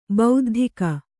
♪ bauddhika